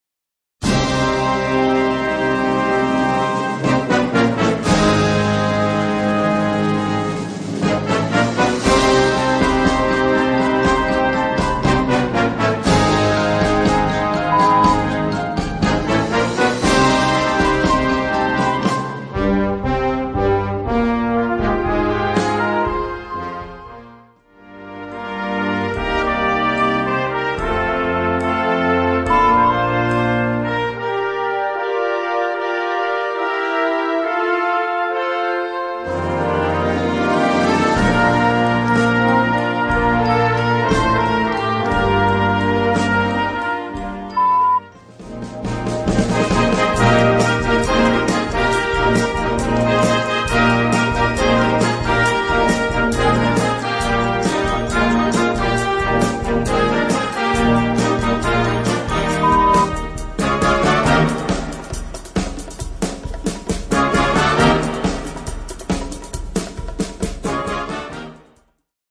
Gattung: Potpourri Konzertant
Besetzung: Blasorchester